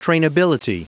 Prononciation du mot trainability en anglais (fichier audio)
Prononciation du mot : trainability